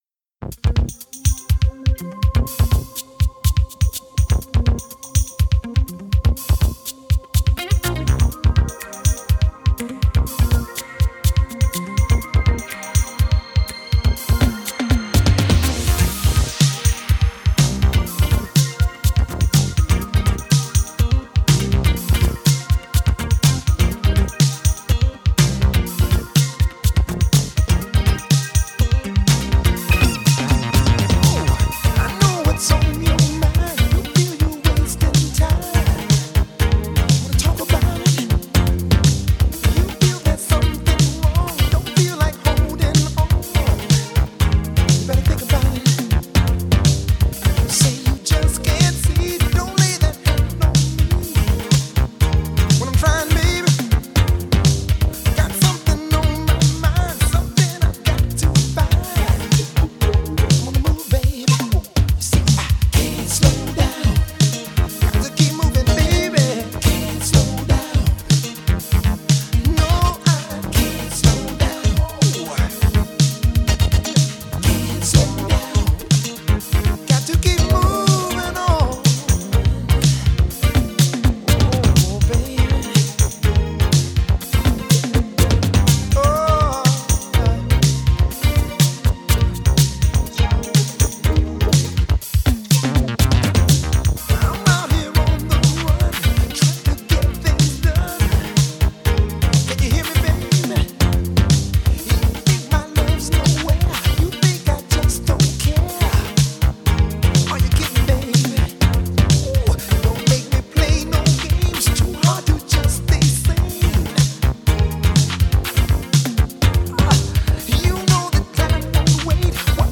Genre: Pop
Electronic Drums [Simmons Drum]
Vocoder